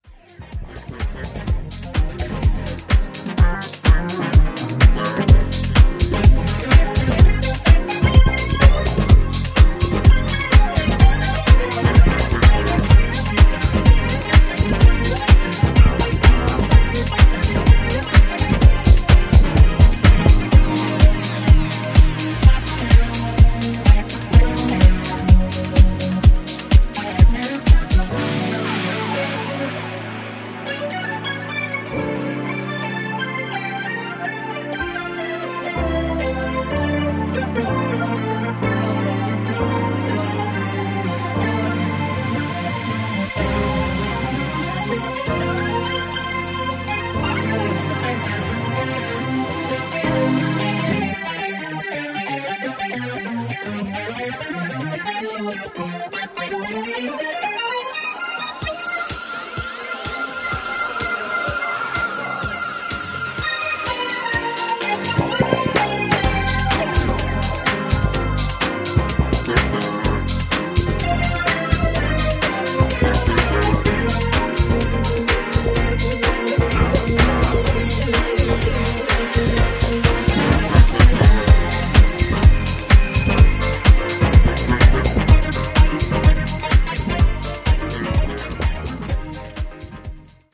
jazzy intricacy